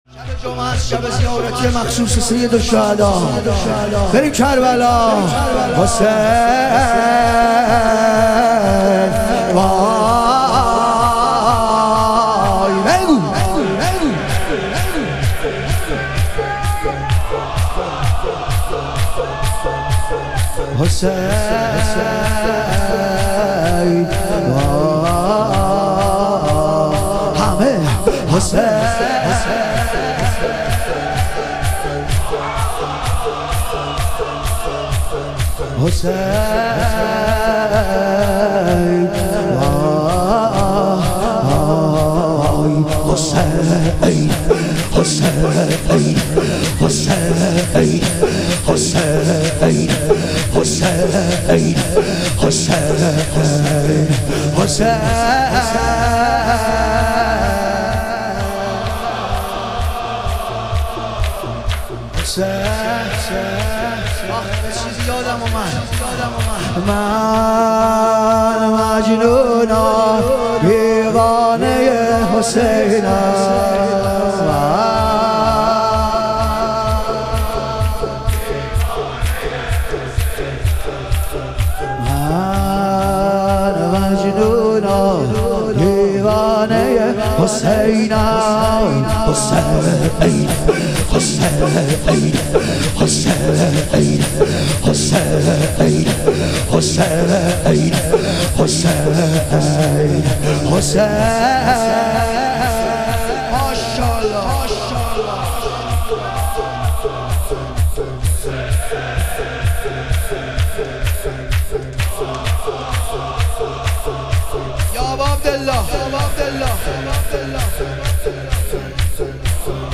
شهادت حضرت جعفرطیار علیه السلام - شور